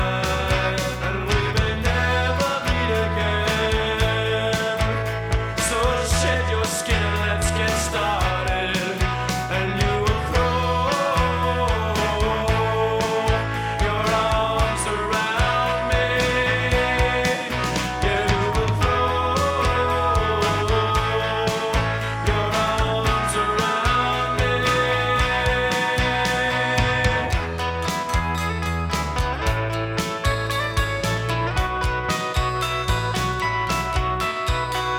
Alternative Rock
Жанр: Рок / Альтернатива